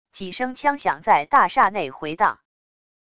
几声枪响在大厦内回荡wav音频